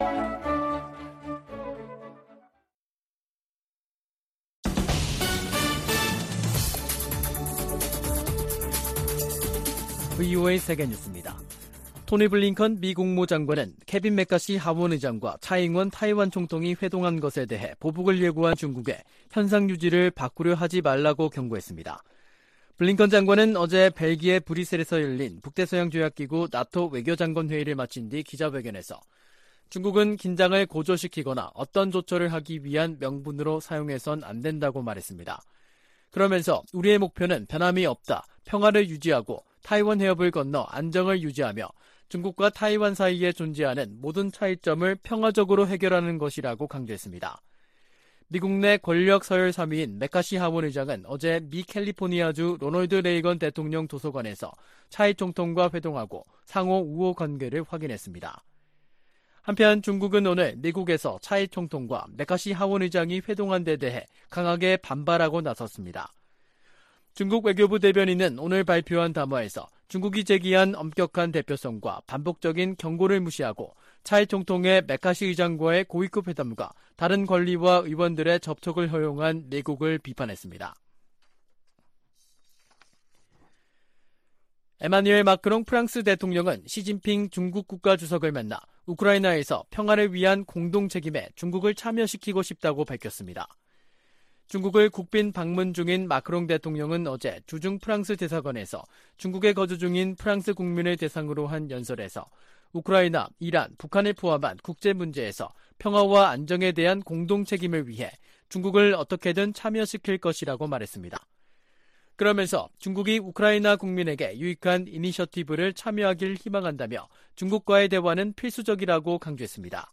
VOA 한국어 간판 뉴스 프로그램 '뉴스 투데이', 2023년 4월 6일 3부 방송입니다. 윤석열 한국 대통령은 최근 북한 간첩단 적발과 관련해 국민들이 현혹되지 않도록 대응 심리전이 필요하다고 강조했습니다. 북한이 아프리카 등지에 계속 군사 장비를 수출하고 있다고 유엔 안보리 대북제재위원회 전문가패널이 밝혔습니다. 한국을 방문한 미국 의원들이 윤석열 대통령을 면담하고 무역과 투자 강화 방안을 논의했습니다.